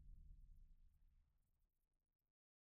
Jump Scare Sting
Jump Scare Sting is a free horror sound effect available for download in MP3 format.
047_jump_scare_sting.mp3